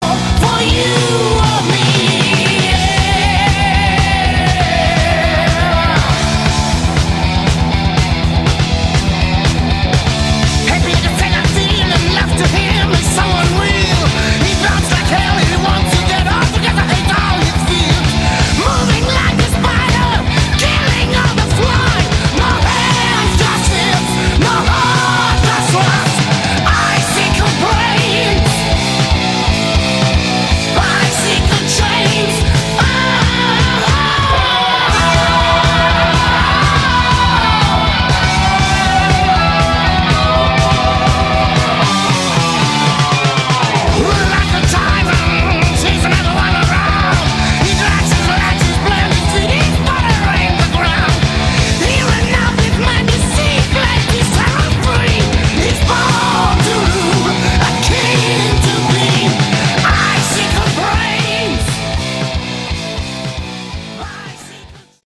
Category: Metal